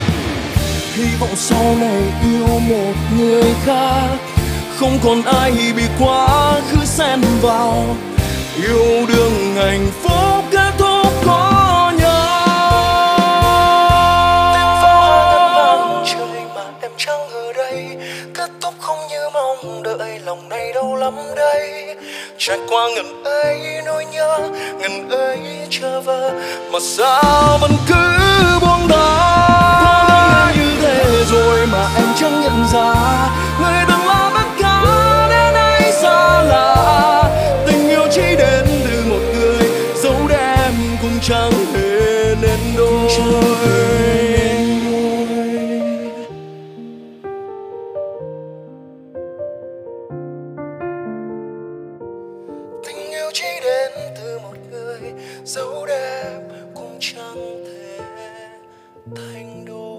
Da diết quá 😞
Nghe buồn quá 😞